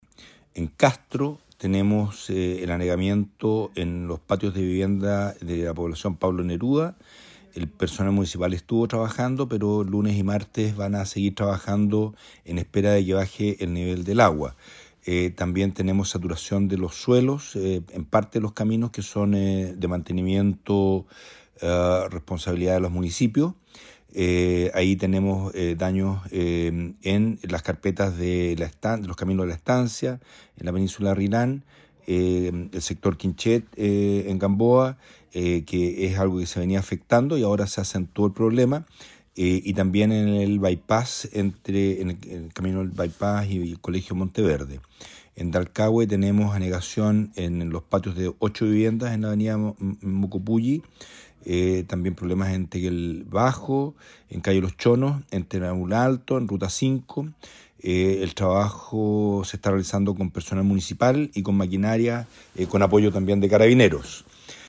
Para la capital provincial y su vecina comuna Dalcahue hubo varios casos de anegamientos e inundaciones, señaló el delegado Marcelo Malagueño.